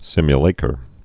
(sĭmyə-lākər, -lăkər)